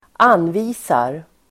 Uttal: [²'an:vi:sar]